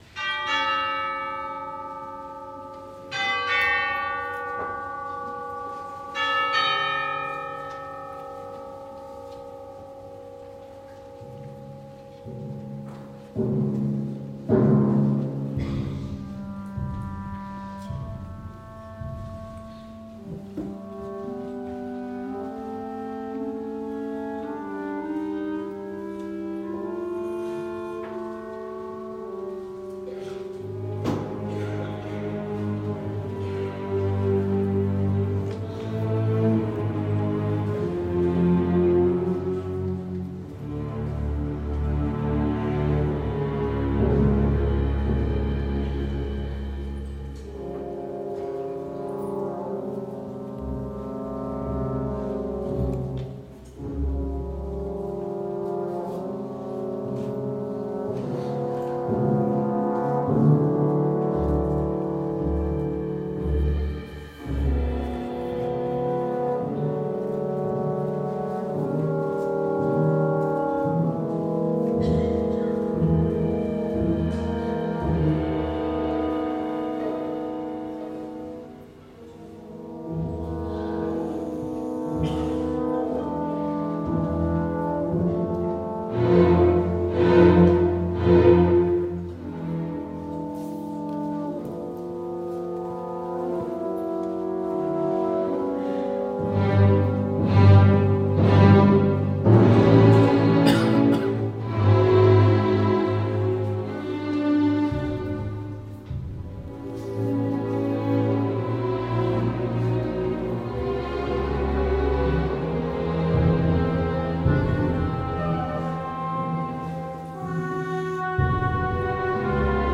Categoría: OBRAS PARA ORQUESTA